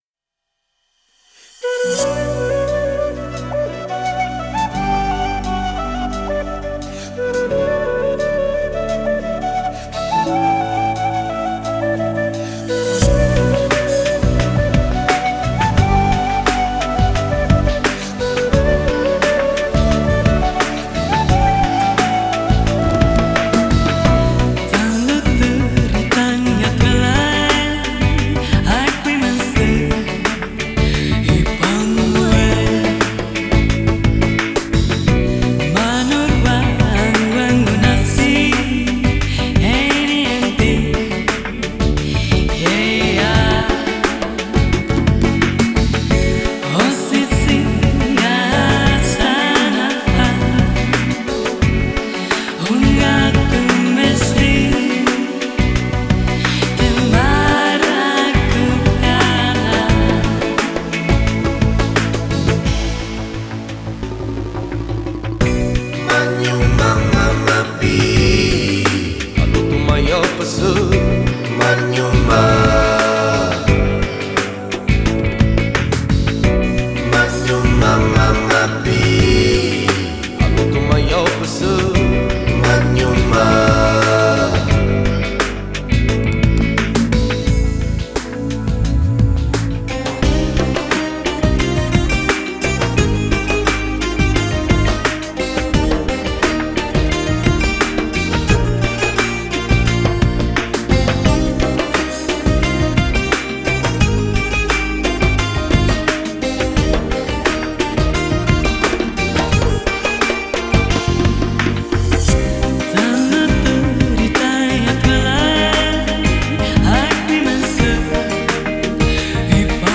New Age Песни шаманов Фолк музыка